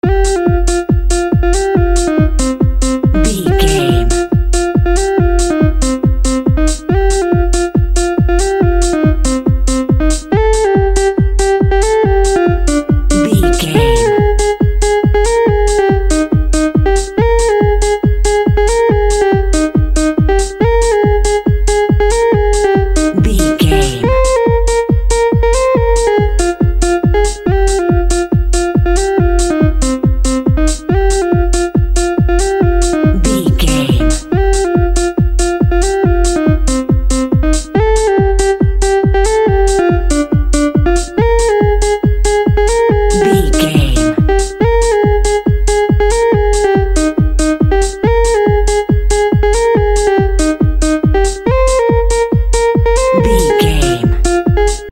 The Sound of Techno.
Aeolian/Minor
groovy
uplifting
bouncy
futuristic
drum machine
synthesiser
house
synth lead
synth bass
synth drums